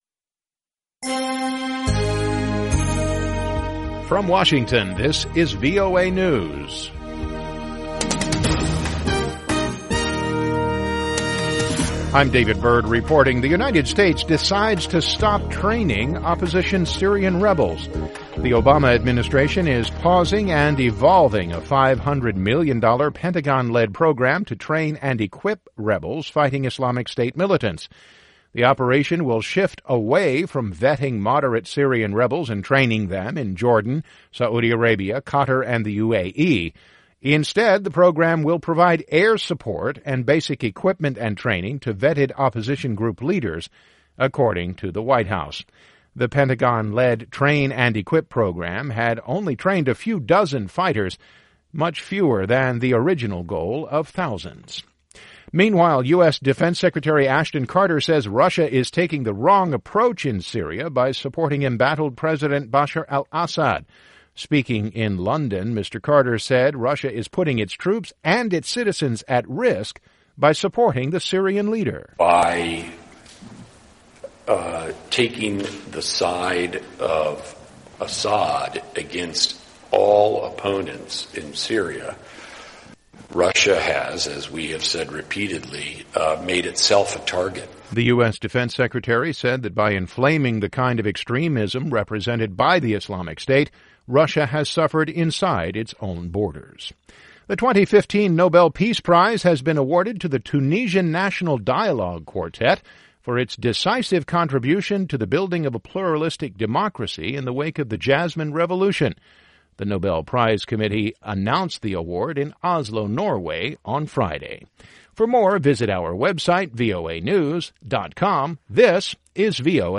VOA English Newscast, 1900 UTC October 9, 2015